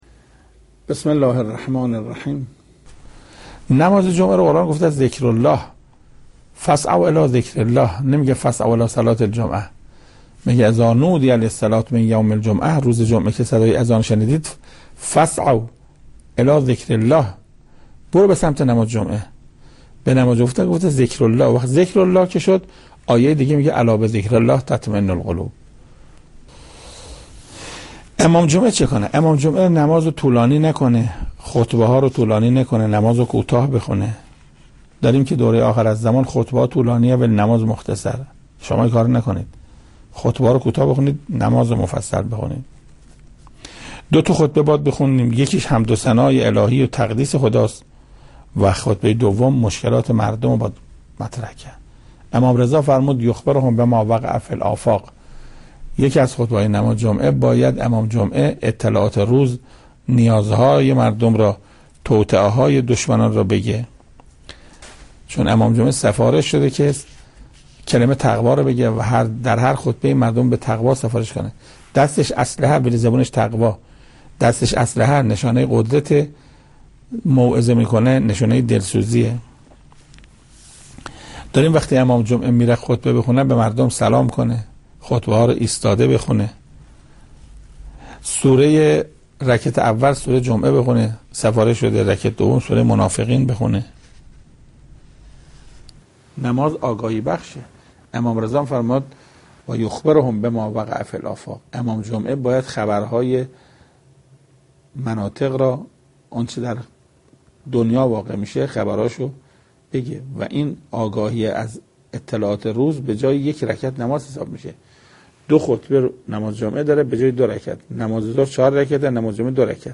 در این قطعه صوتی، شنونده توضیحاتی پیرامون اهمیت نماز جمعه و وظایف امام جمعه در نحوه برپایی این نماز با فضیلت از حجت الاسلام و المسلمین استاد قرائتی باشید.